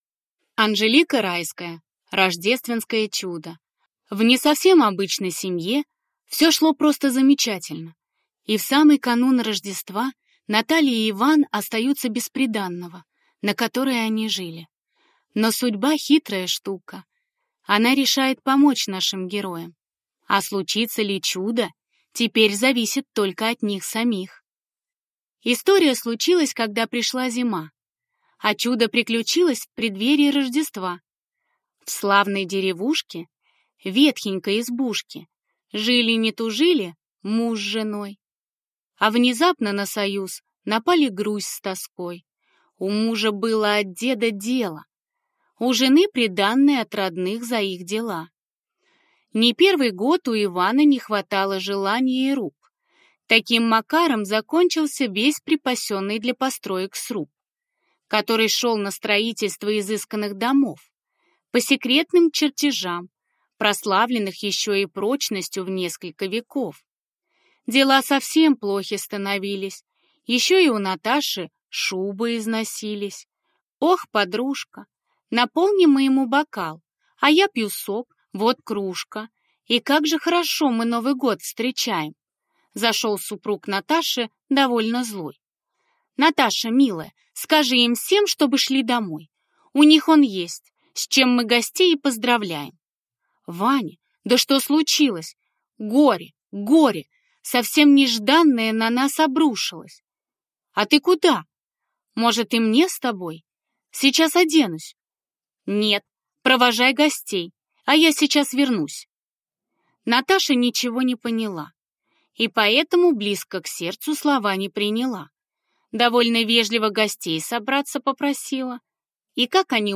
Аудиокнига Рождественское чудо | Библиотека аудиокниг